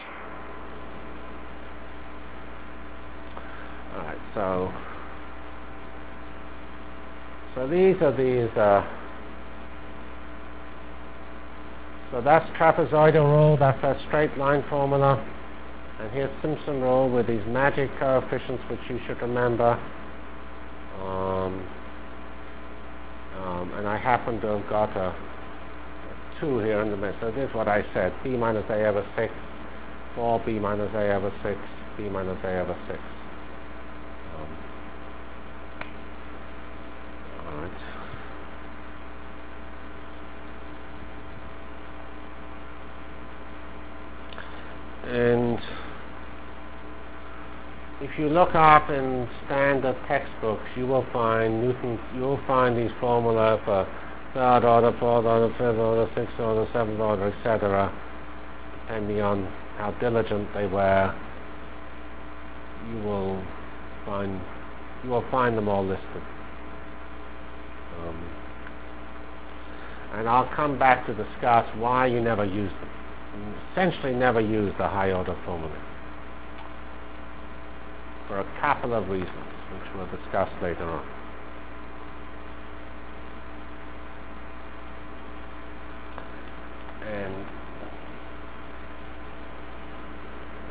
From CPS615-End of N-Body Discussion and Beginning of Numerical Integration Delivered Lectures of CPS615 Basic Simulation Track for Computational Science -- 15 October 96. *